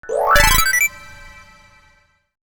UI_SFX_Pack_61_7.wav